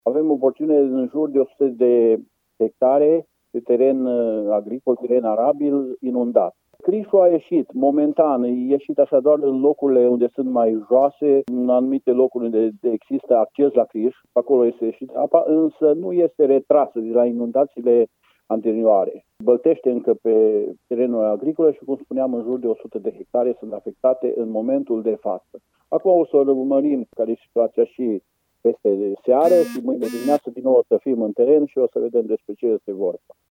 Primarul comunei Almaș, Ginu Aurel Costea: